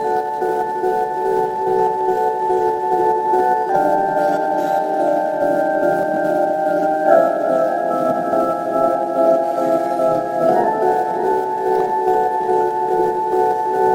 Tag: 100 bpm Chill Out Loops Organ Loops 1.62 MB wav Key : Unknown